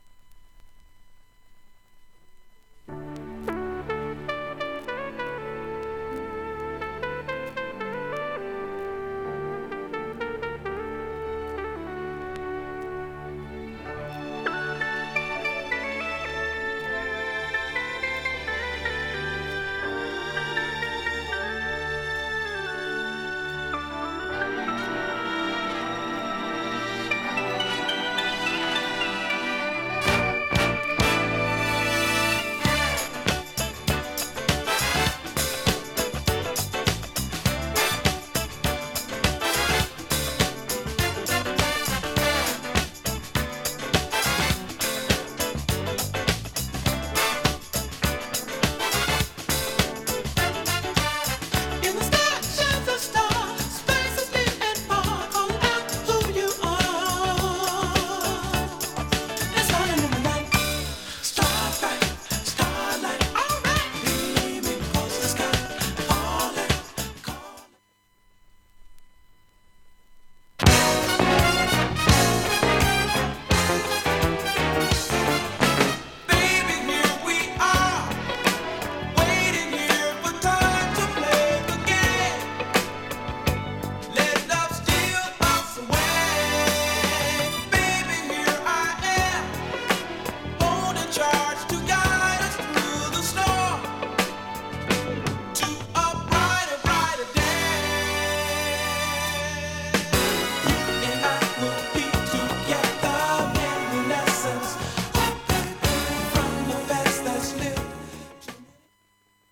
ITALY 盤オリジナル現物の試聴（1コーラス程度）できます。